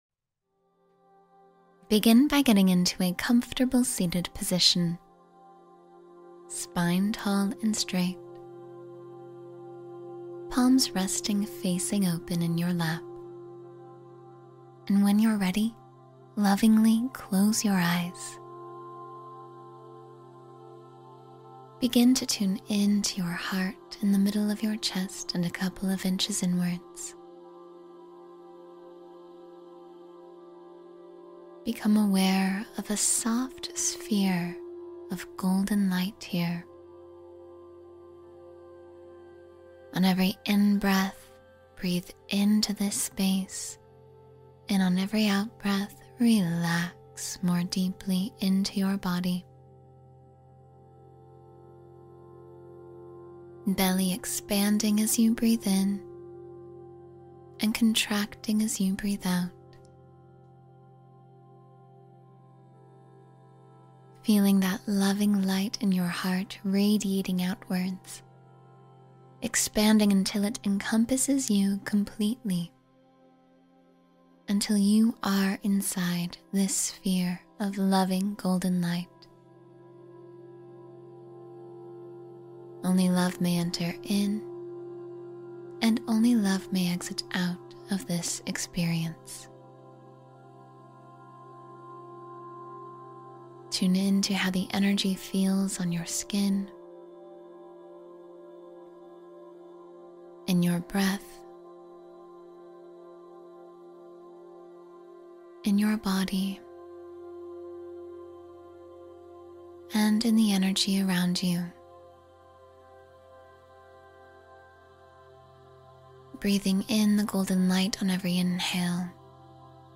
Heal the Inner Child and Restore Joy — 10-Minute Guided Healing Practice